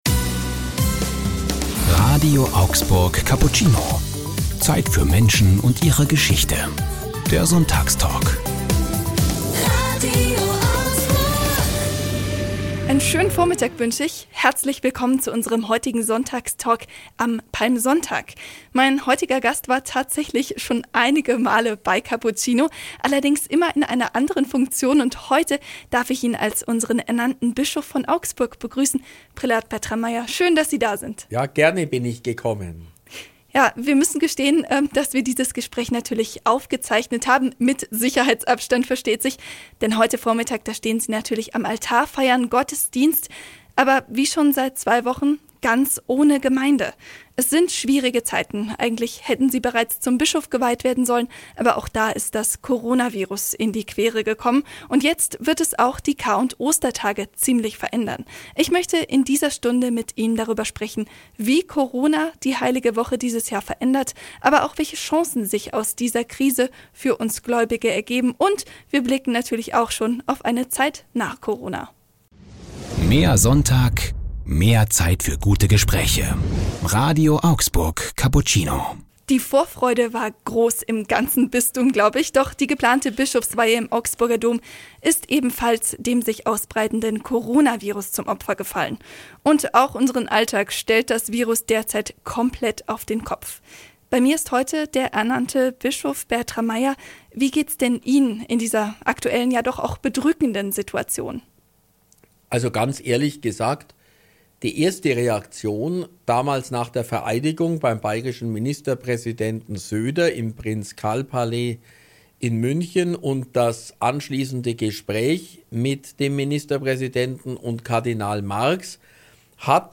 Hier können Sie den ganzen Sonntagstalk nachhören.